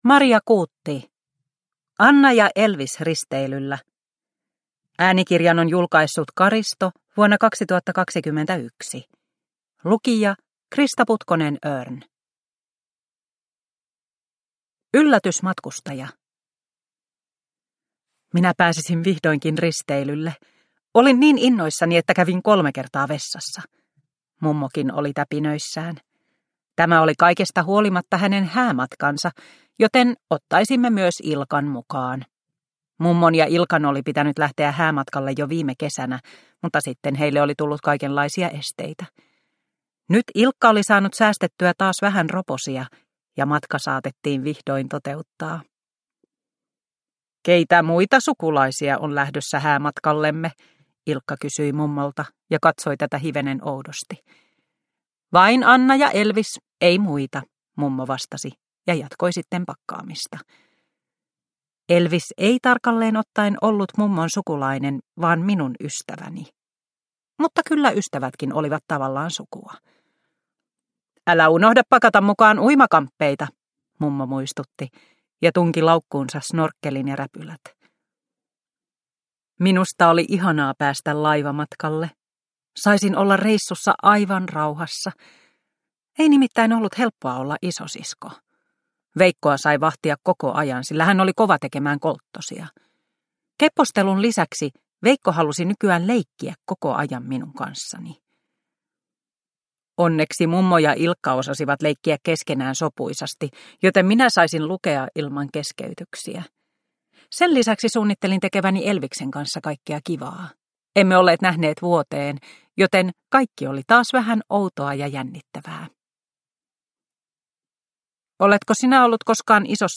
Anna ja Elvis risteilyllä – Ljudbok – Laddas ner